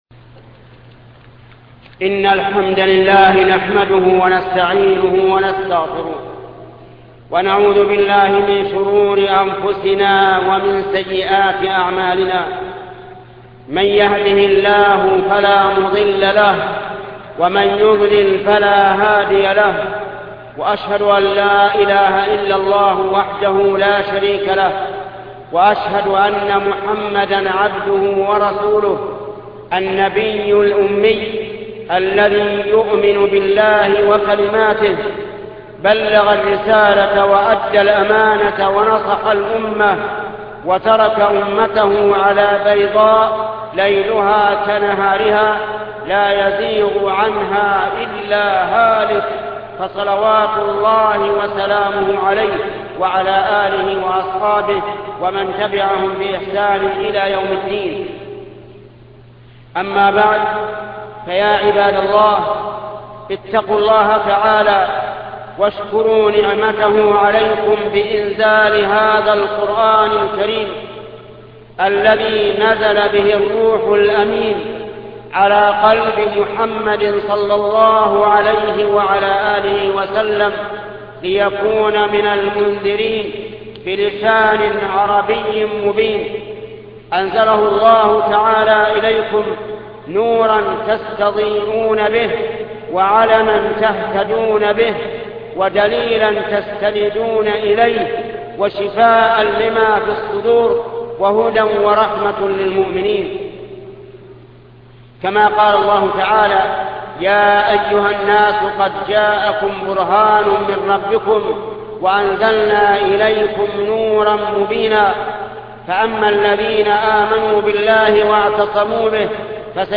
خطبة فضيلة تلاوة القرآن ـ فضل صوم يوم عاشوراء الشيخ محمد بن صالح العثيمين